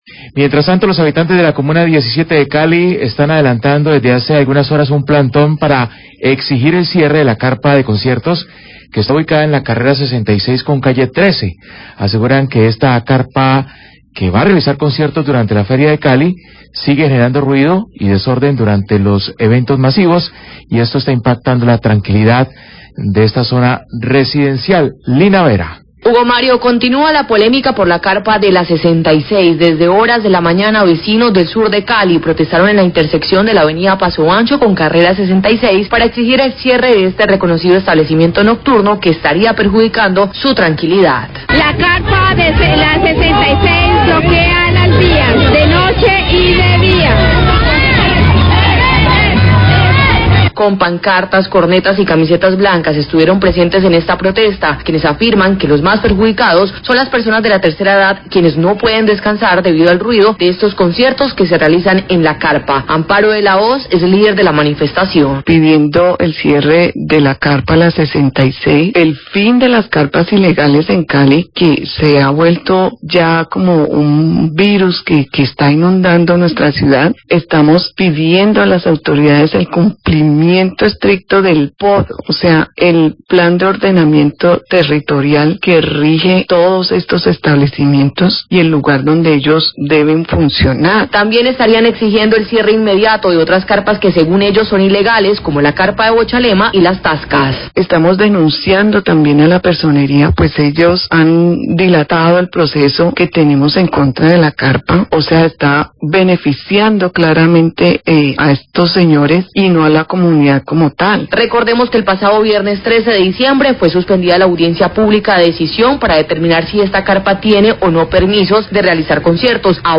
Radio
Habitantes de la Comuna 17 de Cali, adelantan un plantón sobre la Ave. Pasoancho para exigir el cierre de actividades de la Carpa La 66. Audios de los residentes denunciando las afectaciones por ruido de los conciertos en esta carpa.